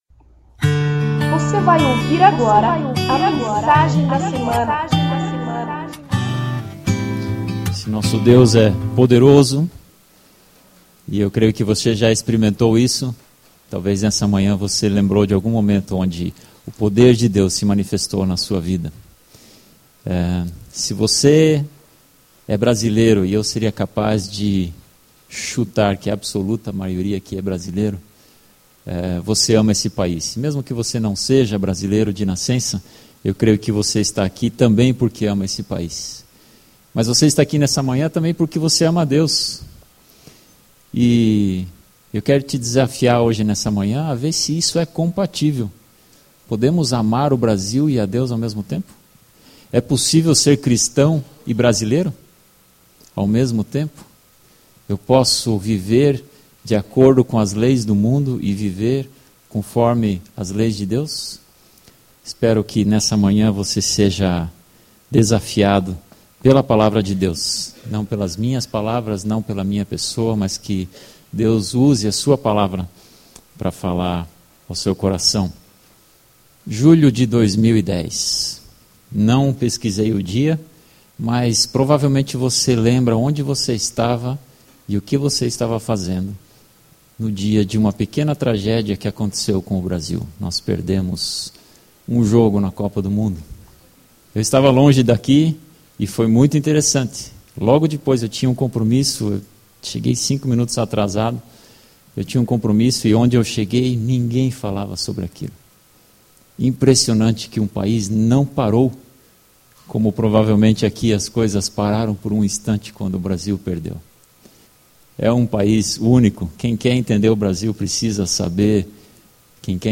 O desafio de ser cristão no Brasil (Romanos 12:1-2) · Objetivo da mensagem: apontar para a soberania de Deus, mesmo em meio ao caos social que parece tomar conta do nosso querido Brasil.